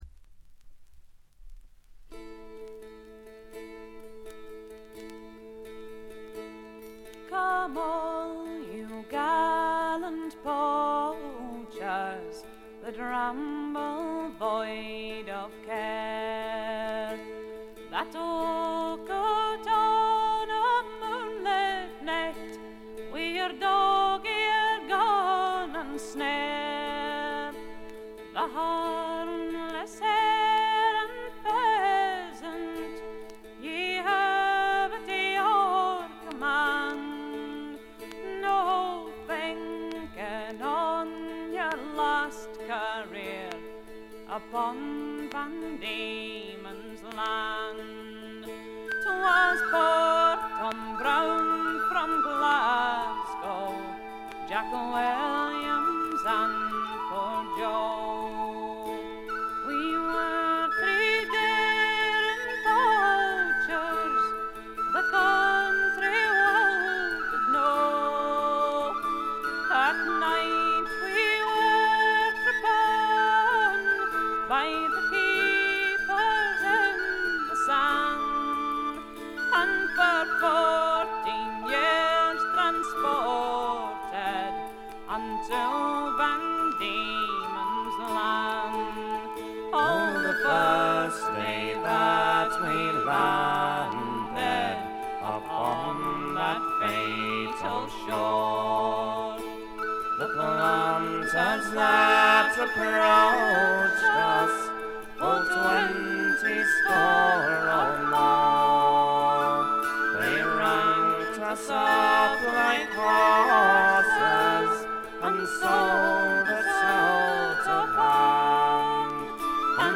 スコットランドはエディンバラの4人組トラッド・フォーク・グループ。
試聴曲は現品からの取り込み音源です。